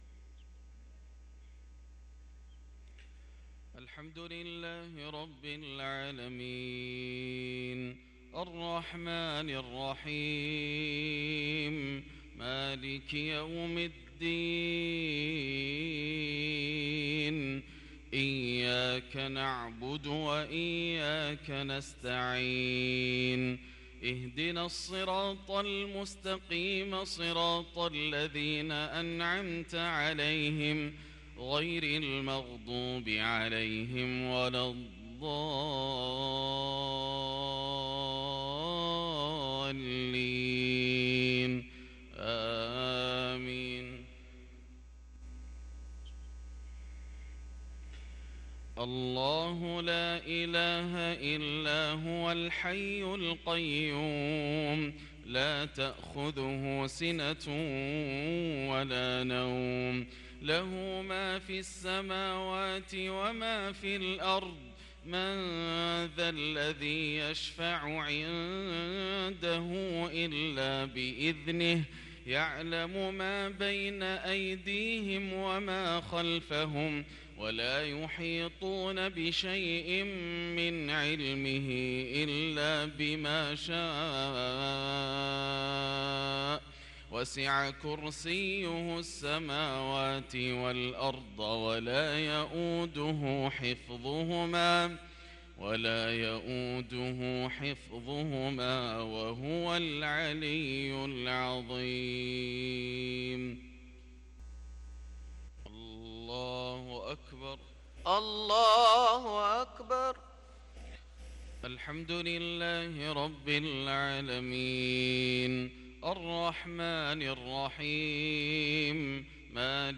صلاة المغرب للقارئ ياسر الدوسري 19 صفر 1444 هـ
تِلَاوَات الْحَرَمَيْن .